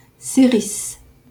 Serris (French pronunciation: [sɛʁis]